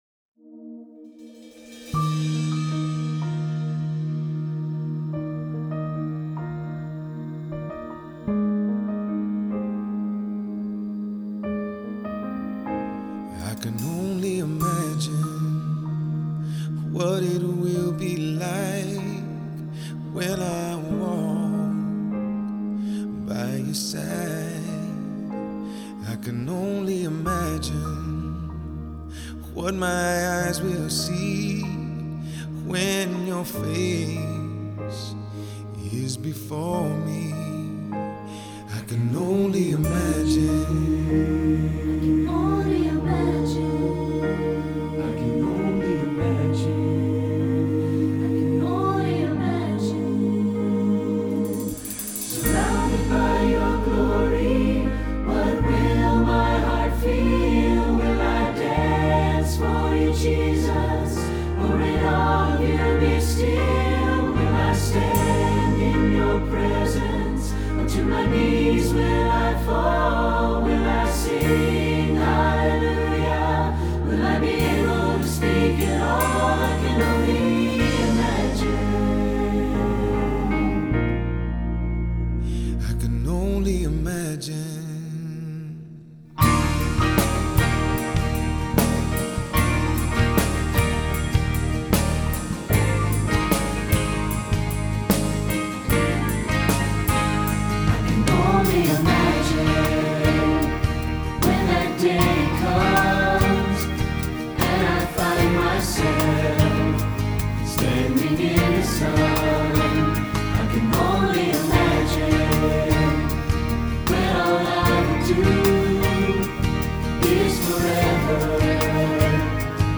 Choral Church
SATB